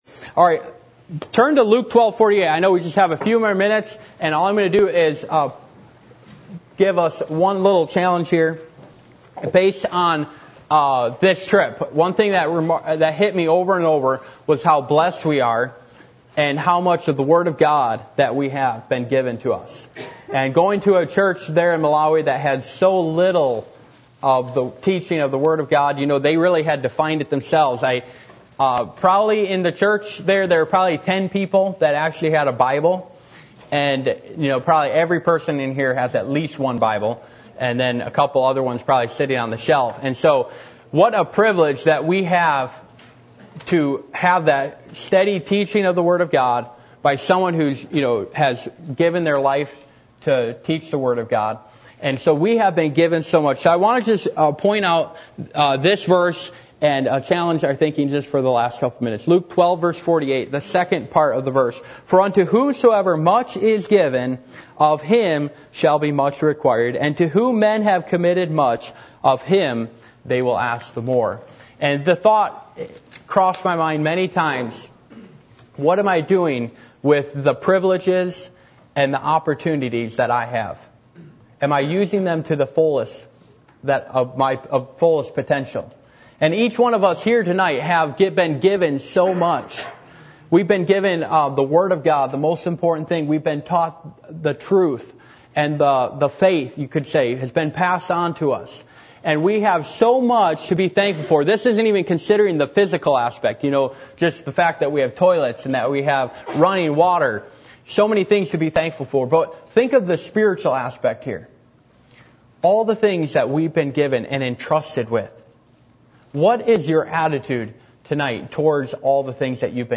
Luke 12:24 Service Type: Midweek Meeting %todo_render% « What Will The Coming Of Christ Be For You?